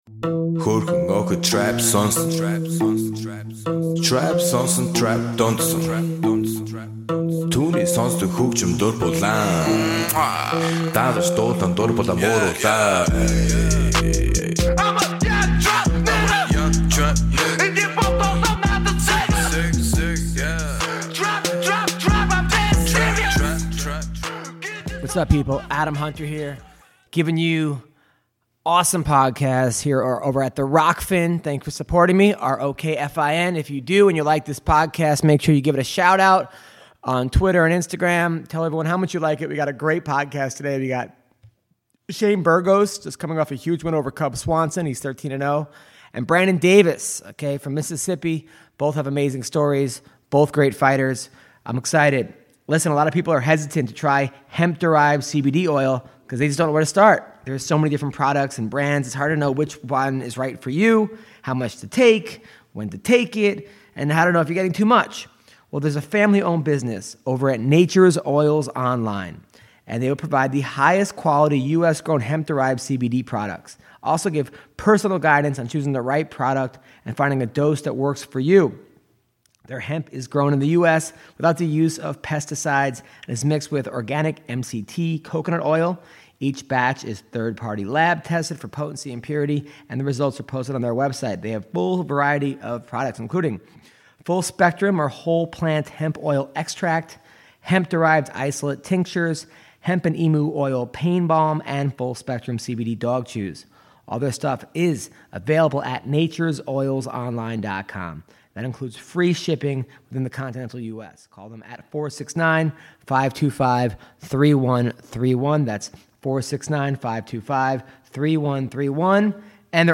takes calls from MMA fighters